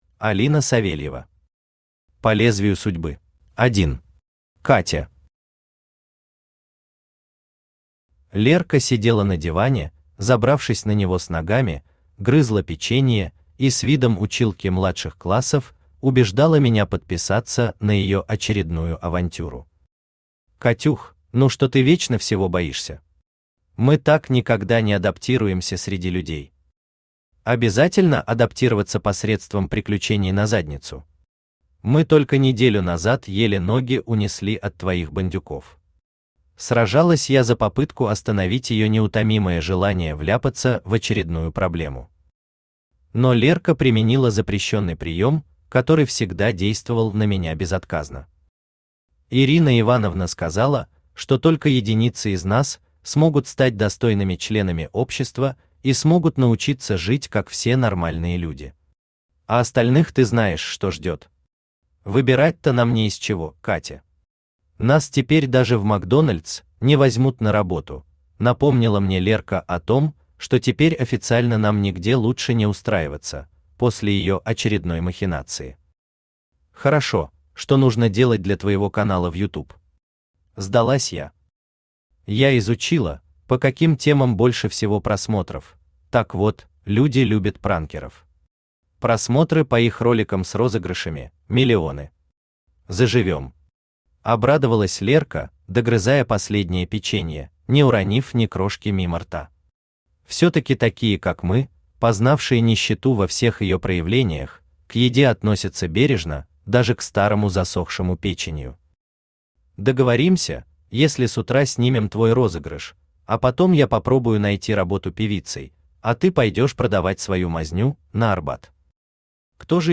Аудиокнига По лезвию судьбы | Библиотека аудиокниг
Aудиокнига По лезвию судьбы Автор Алина Савельева Читает аудиокнигу Авточтец ЛитРес.